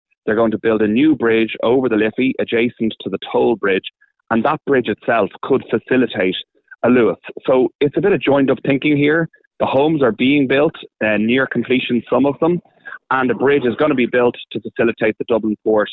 Deputy Geoghegan says the Luas extension could easily fit into the Port's plans: